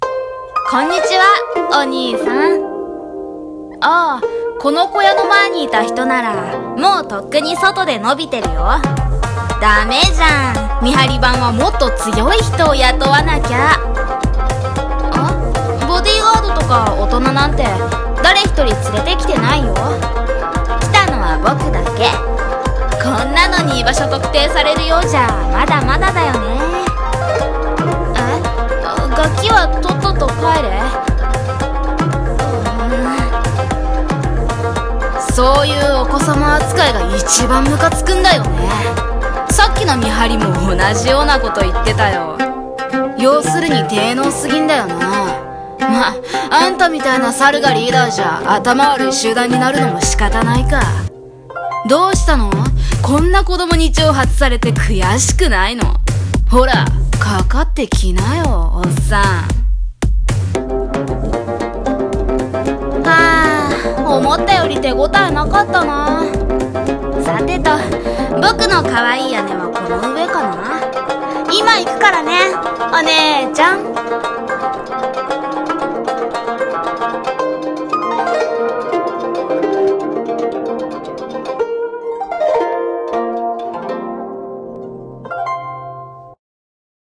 【朗読】お子様ヒーロー